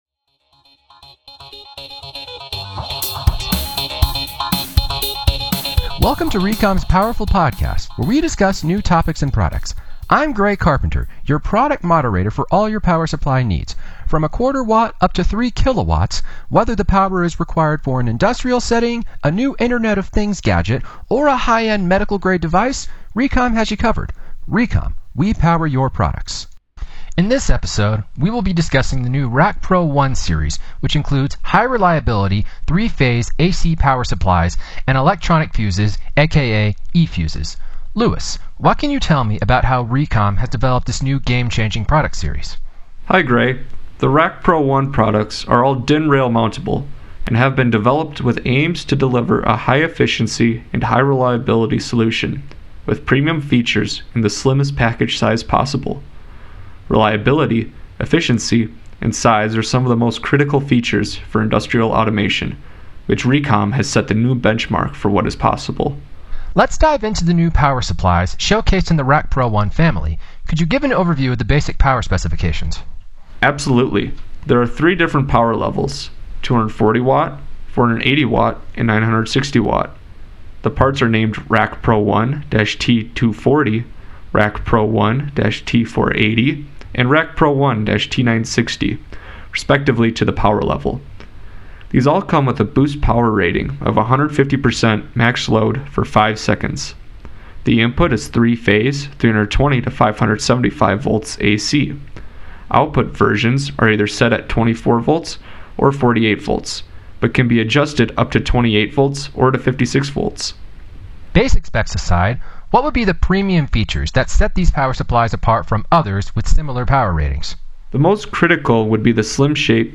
播客文稿